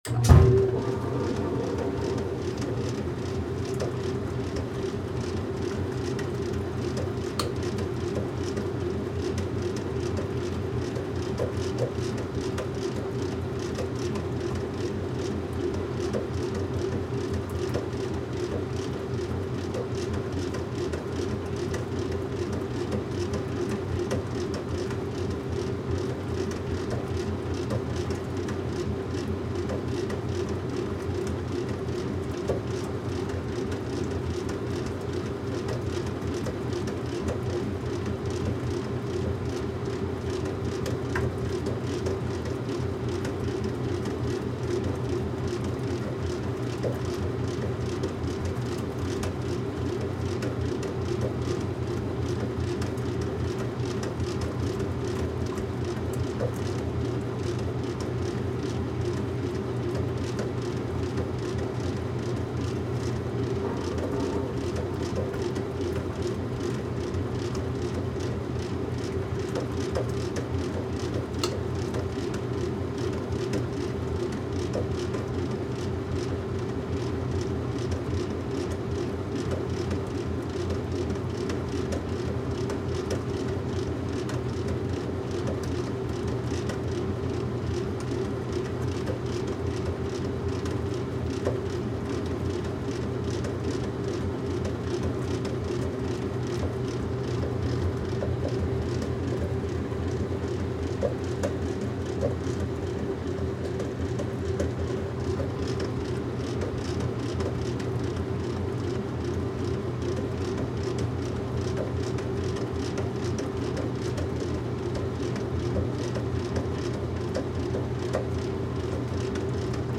washingMachine.wav